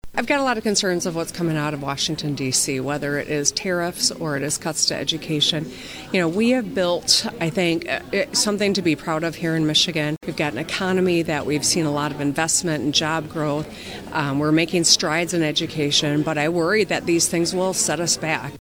Whitmer said in metro Detroit yesterday that “there doesn’t seem to be a rationale, that is a long-term goal.”